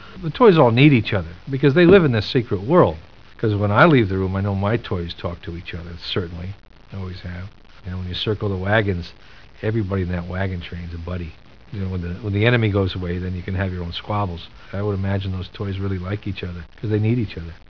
The Voice of Hamm
THE ACTOR SPEAKS